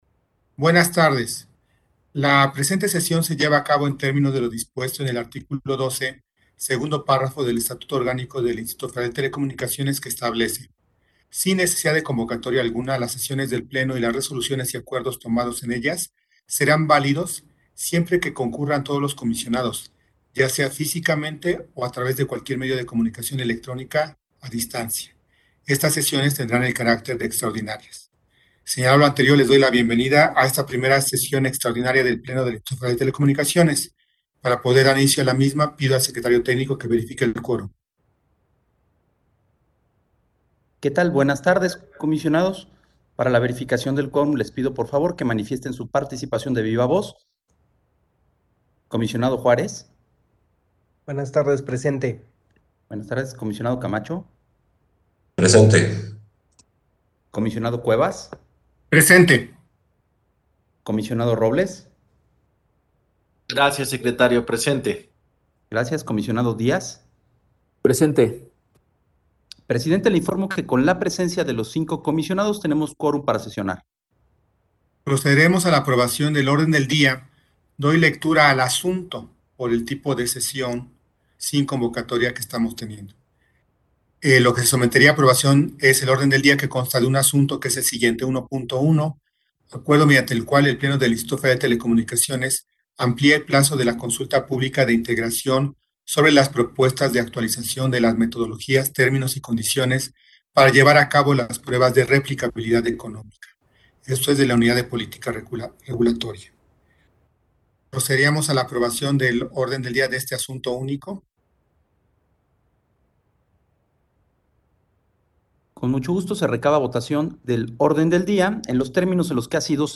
Audio de la sesión